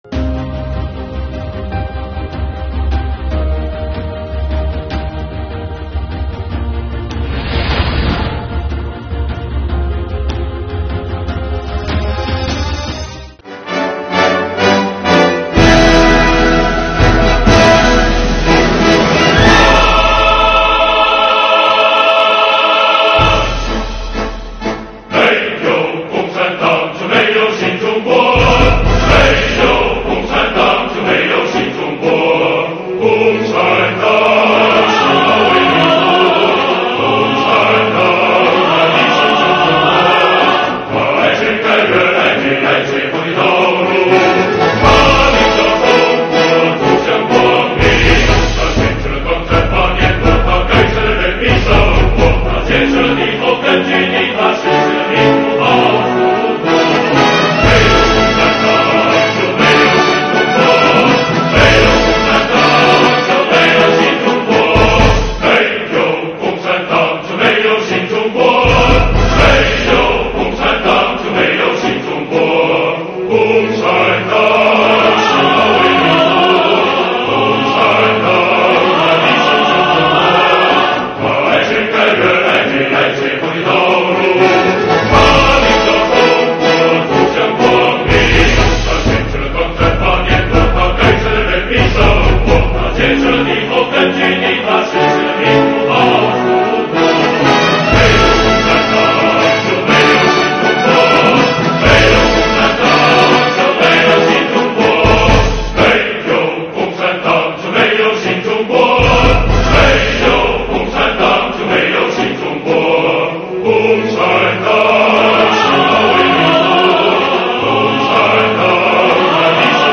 按照市局“赓续百年红色辉煌路、学思践悟筑梦税务蓝”活动的计划安排，市局于近期举办“唱支心歌给党听”活动，以经典作品大合唱的方式，引导广大党员干部用嘹亮的歌声致敬党的历史，唱出税务铁军热爱党、热爱祖国的浓浓深情。